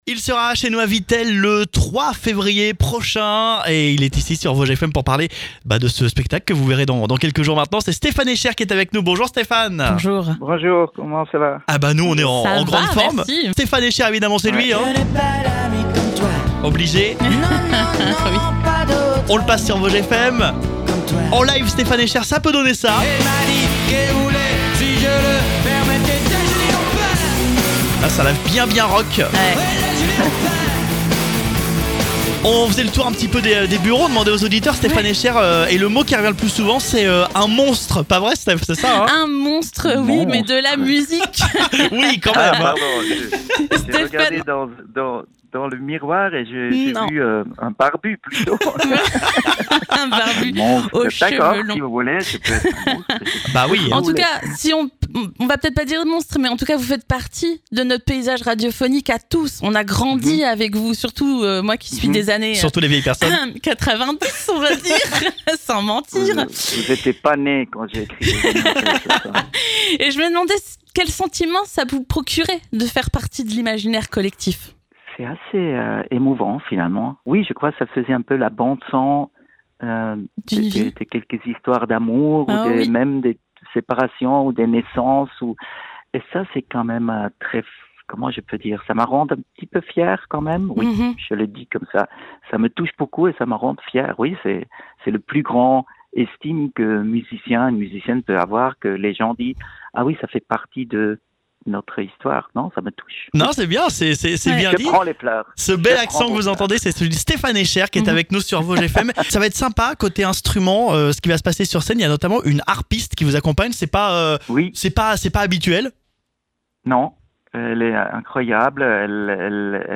Rencontre : Stephan Eicher se confie avant son concert à Vittel !